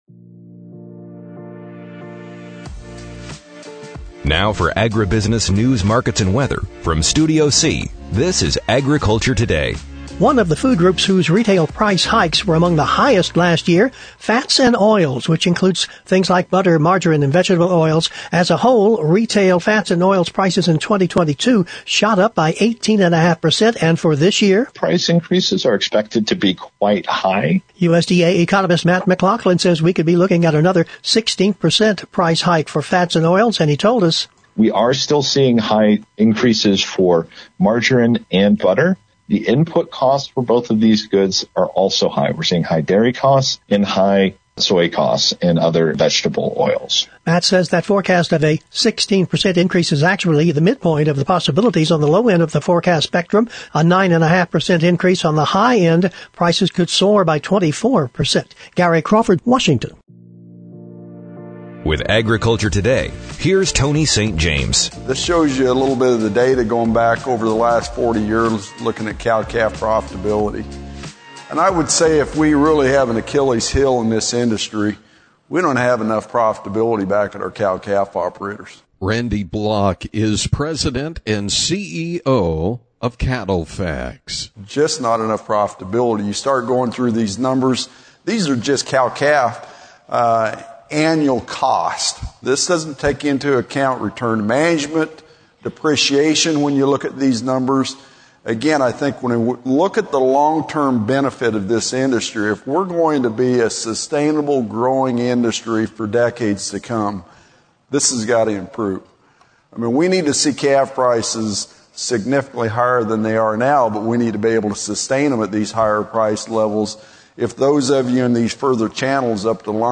AGRICULTURE TODAY PODCAST (from the Cattle Industry Convention & NCBA Trade Show in New Orleans, LA) Today’s episode examines cow-calf profitability, farm bill, the hit TV series Yellowstone, La Nina, and crop insurance.